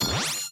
battle_item_use.mp3